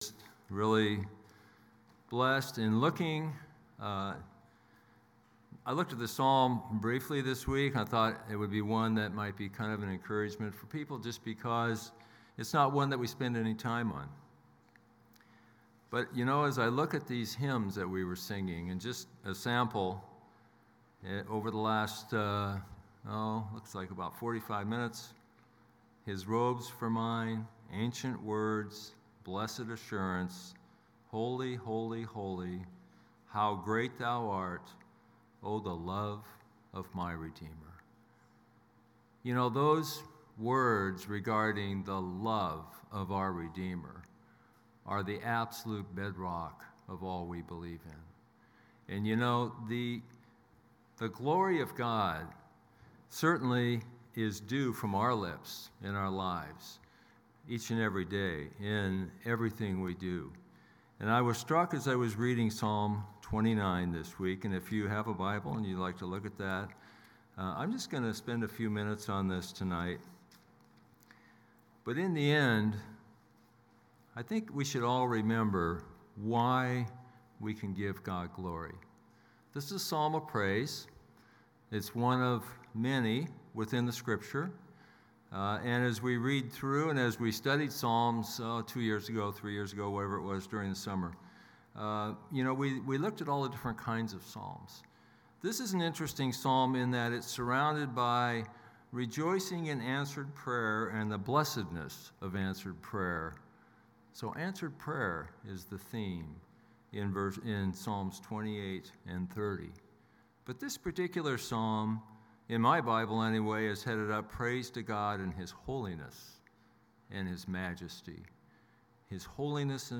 Hillcrest & Trinity Hymn Sing and Devotional